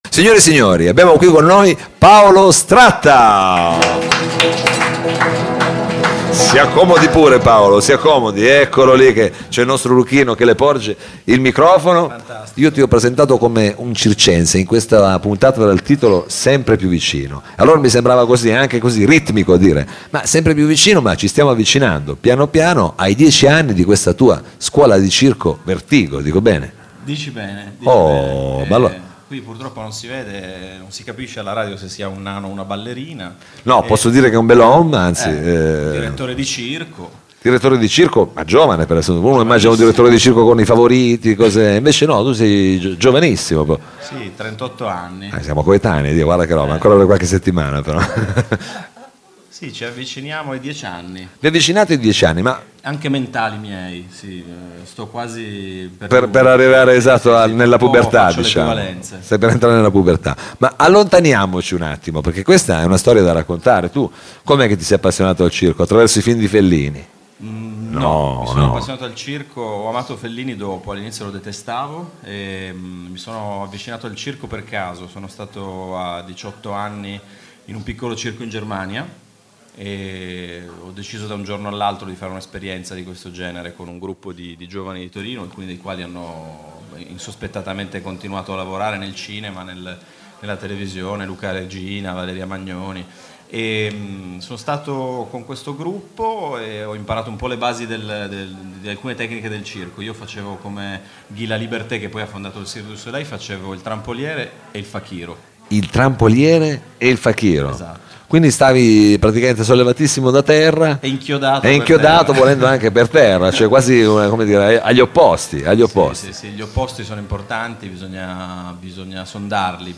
programma radiofonico